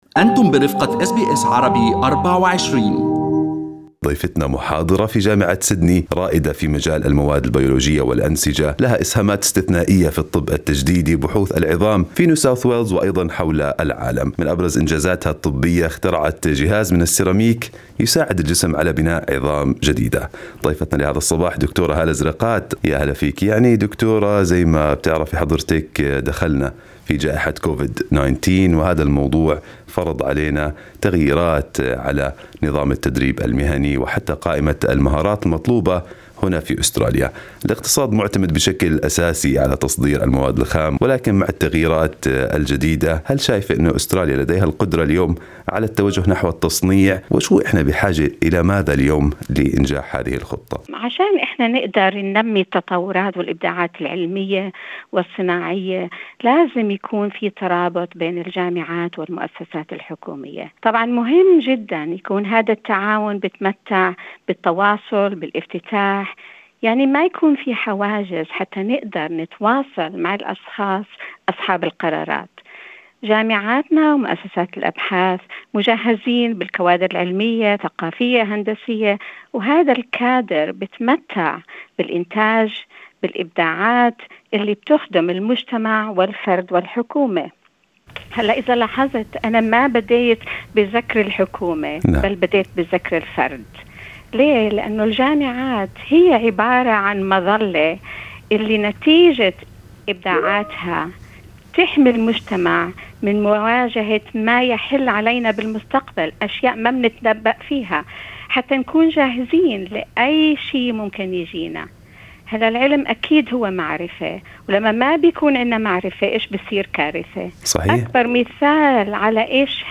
وفي حديث لها مع أس بي أس عربي24